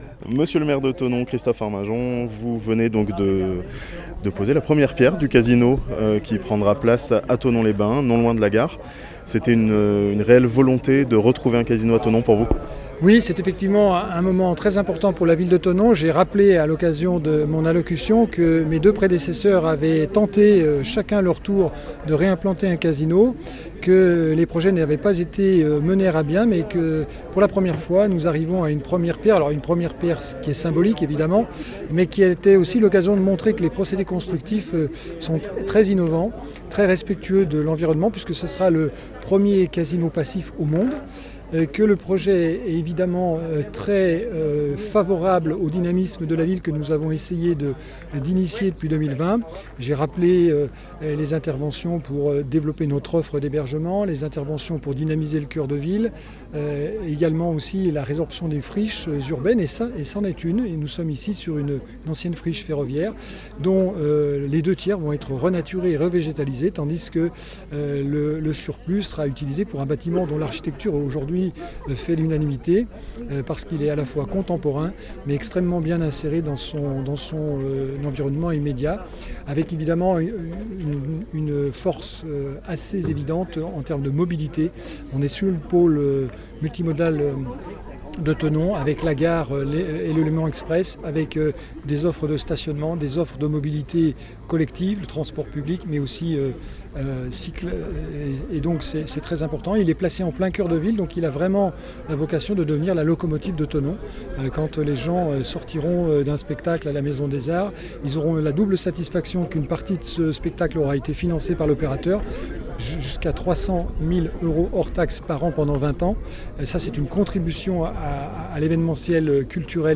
Christophe Arminjon, maire de Thonon-Les-Bains, au micro La Radio Plus